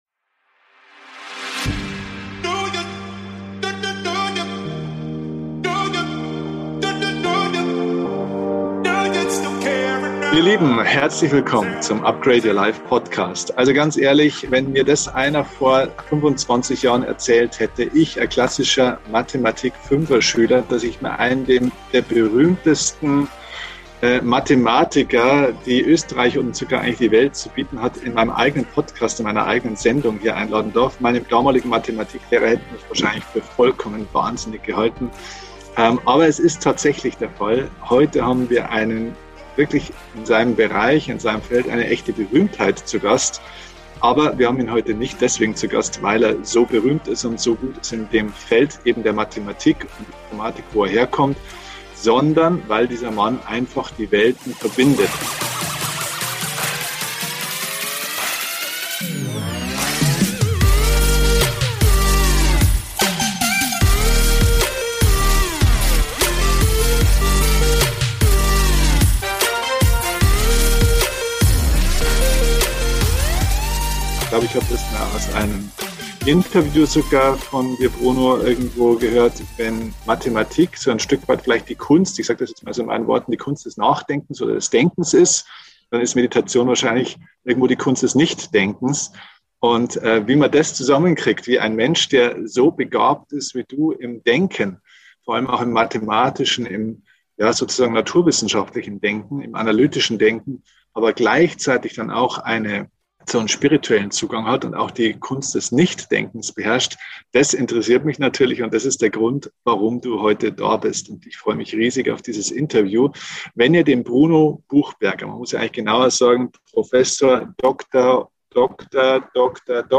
In unserem gemeinsamen Gespräch spricht er neben Mathematik über transzendentale Meditation und über das Bewusstsein der Menschen. Dr. Bruno Buchberger ist emeritierter ordentlicher Professor für Computer-Mathematik in Linz.